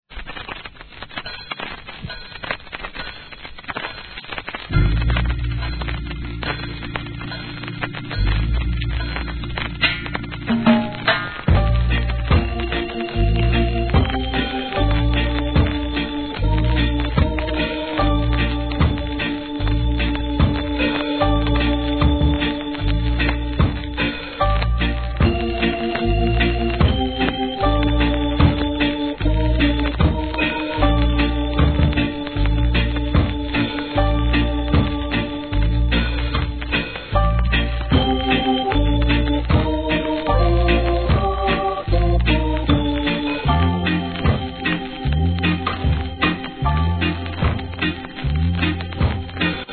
序盤にややチリ目立ちますが問題ないと思います
REGGAE